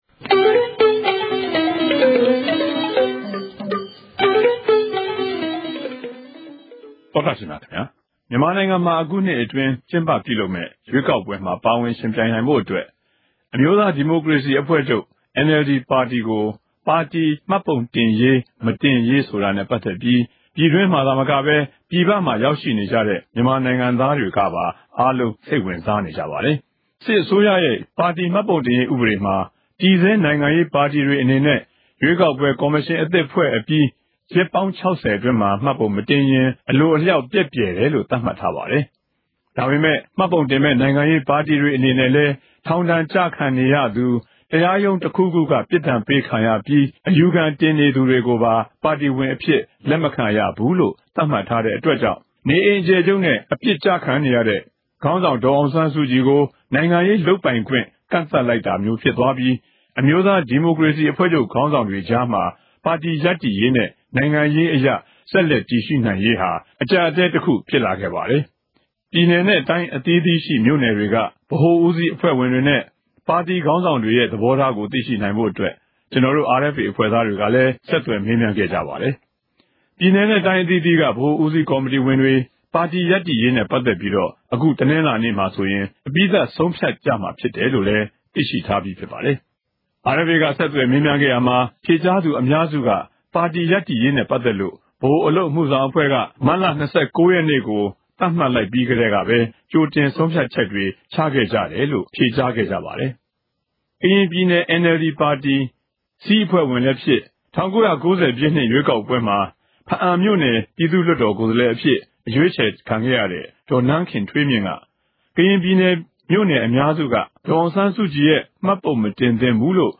အာအက်ဖ်အေ ဝိုင်းတော်သားတေရြဲႚ ဆက်သြယ်မေးူမန်းခဵက်တေနြဲႚ အတူ စုစည်း တင်ူပထားပၝတယ်။